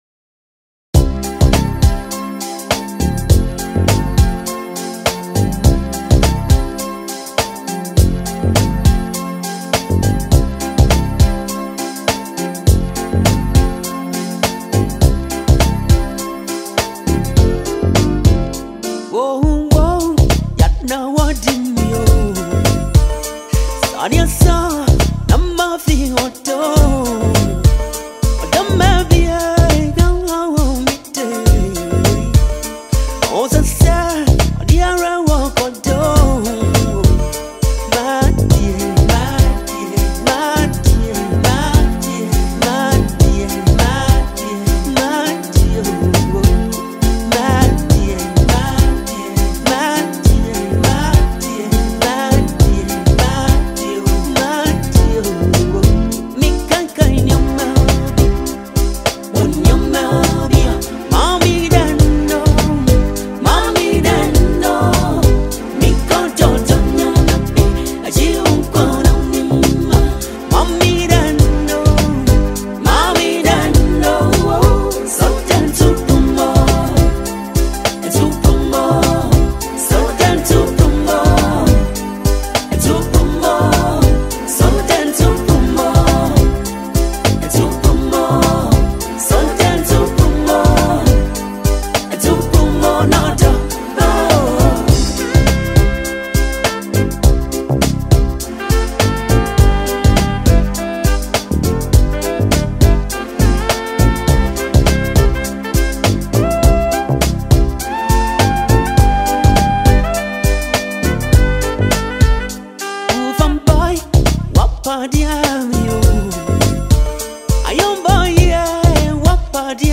highlife
is a beautifully composed highlife track